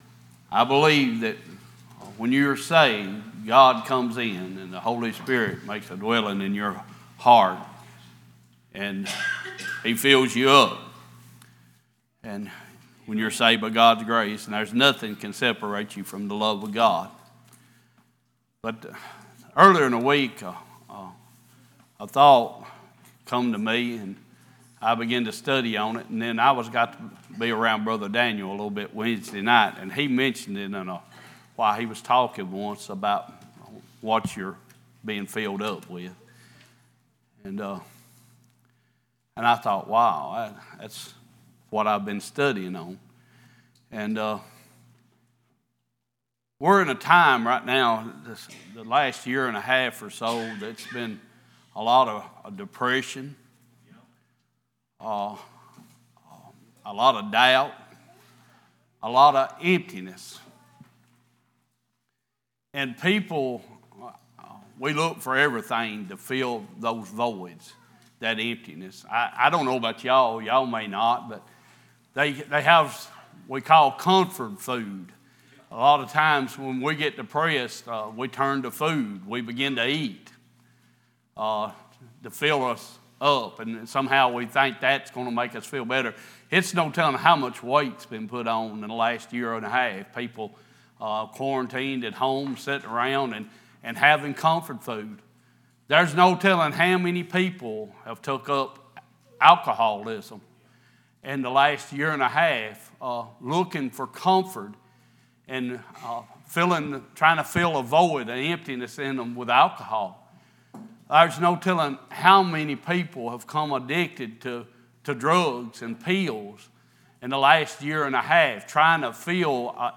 Sunday Morning Passage: The Book of Ruth, Psalms 81:8-16, Matthew 5:6 Service Type: Worship « A Study of James